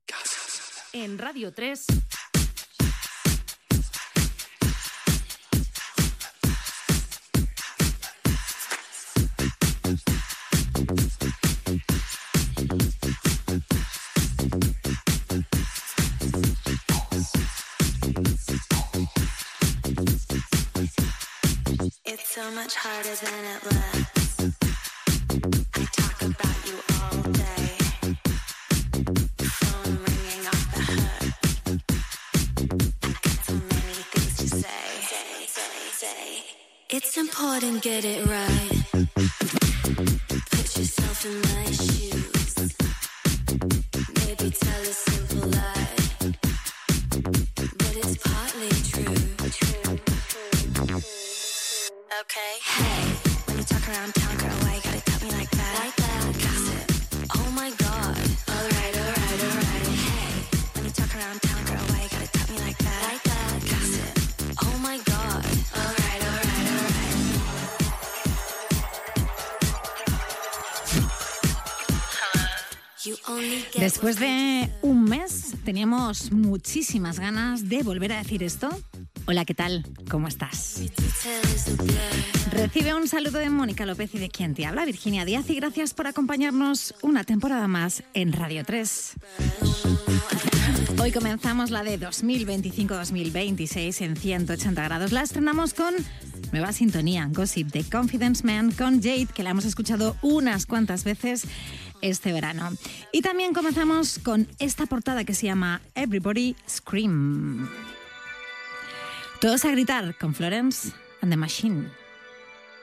Sintonia, inici del programa i de la temporada 2025-2026, comentari de la sintonia i tema musical
Musical